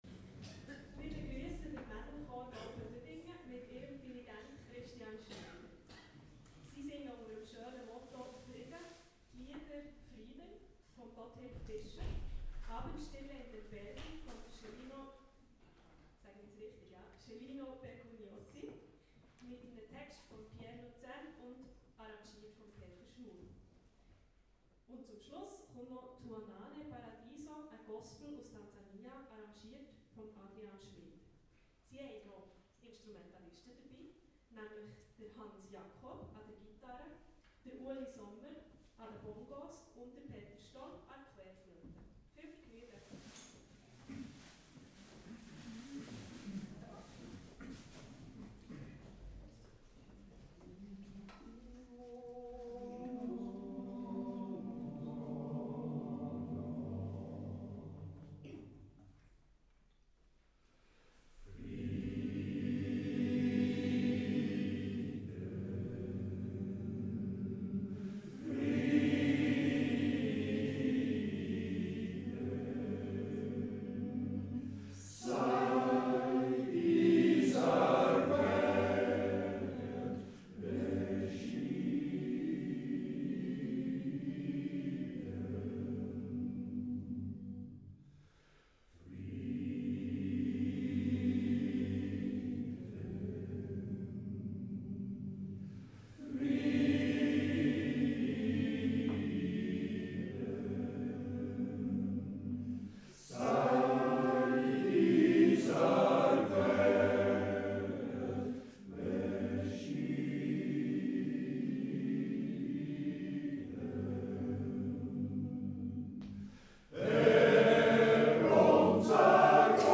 Schweizerisches Gesangsfest in Meiringen (Fr/Sa 19./20. Juni 2015
Wir singen vor Experten an diesem Grossanlass und lassen uns bewerten.
Aufnahme unsere Wettlieder:
01-meiringen-mcld.mp3